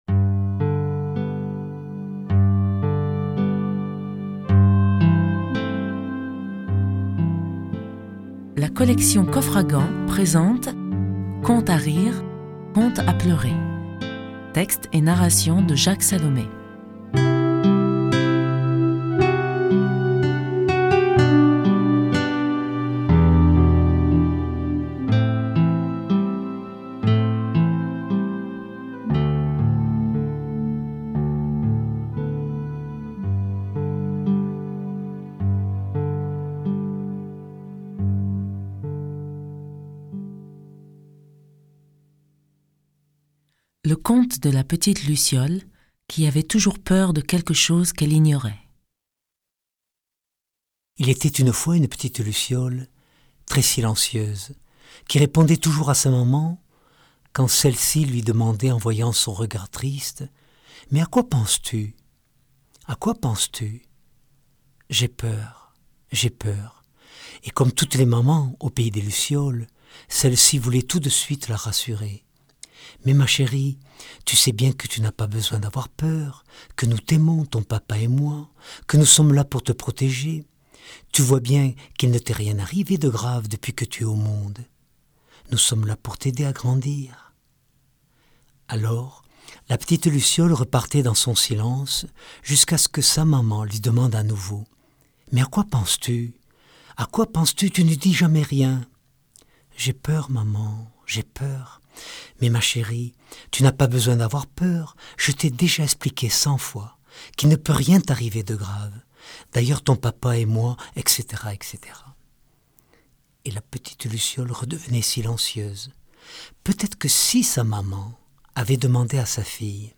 Un livre audio puissant et merveilleux, rempli d’histoires qui vous entraînent vers la réflexion, la compréhension et l’apaisement des maux de notre vie. Lu par Jacques Salomé Durée : 1h20 14 , 40 € Ce livre est accessible aux handicaps Voir les informations d'accessibilité